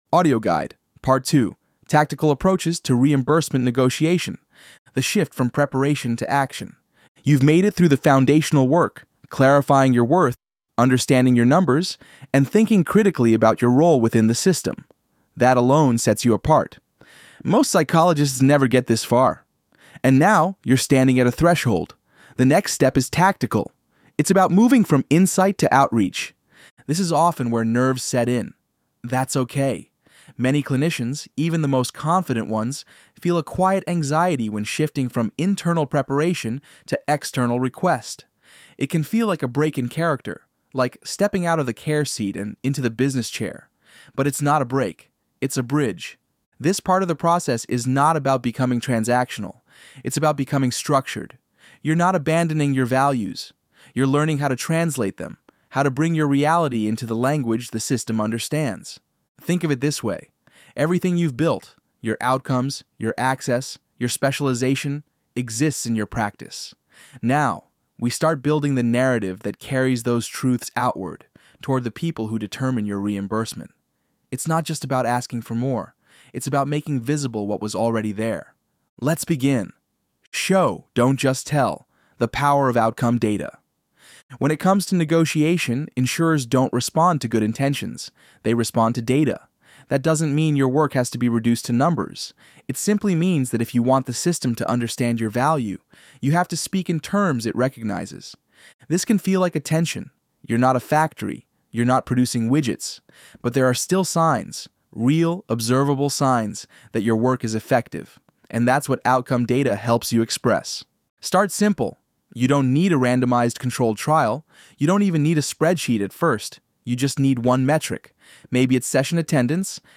Part 2- Audio Summary
ElevenLabs_Untitled_Project-2.mp3